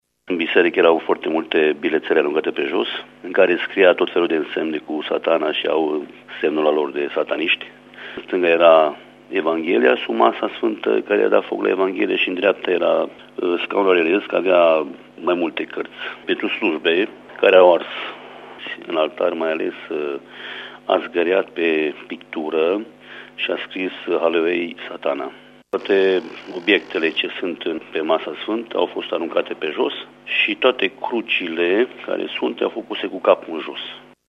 Primarul din Lunca Bradului, Petru Vultur: